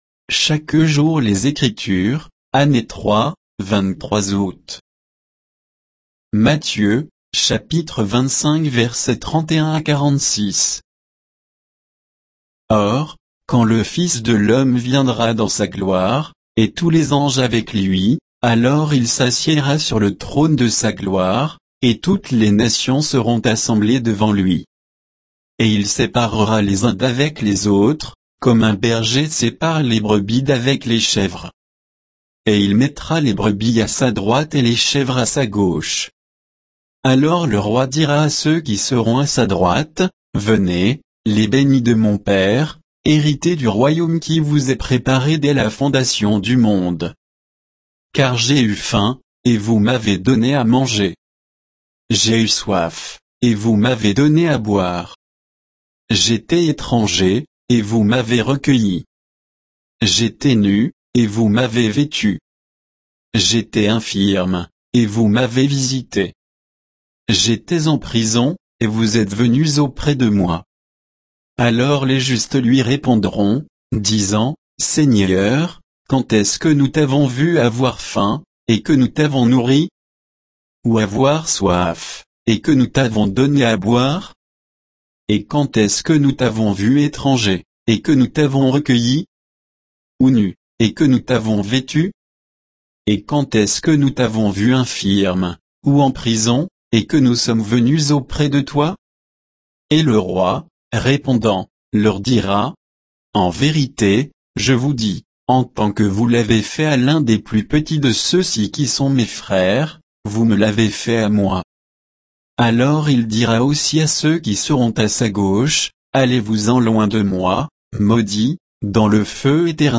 Méditation quoditienne de Chaque jour les Écritures sur Matthieu 25